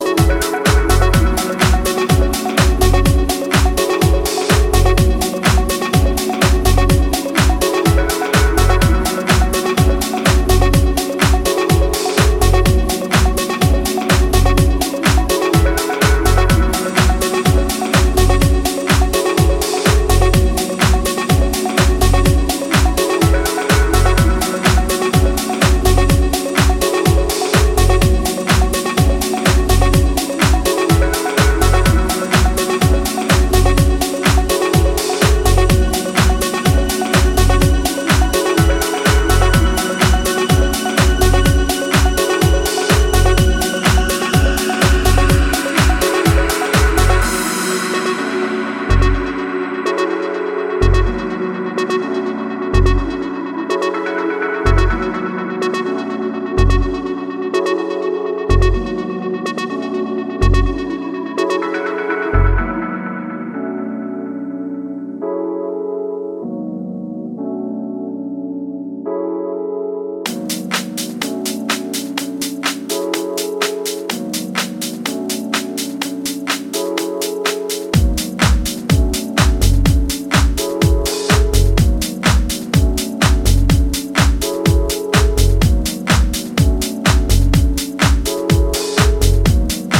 クラシカルでオーセンティックな魅力を放つ、ソリッドなディープ・ハウス群を確かな手腕で展開しています。
ジャズキーの効いたピュアなディープ・ハウス